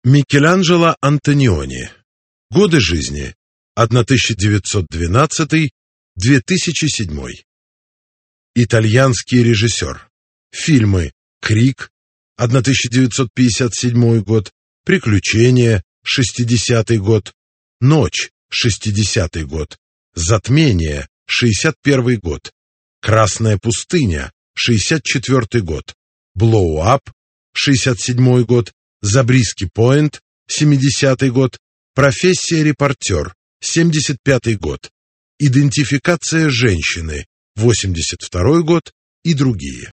Аудиокнига Режиссеры-1 (Станиславский…)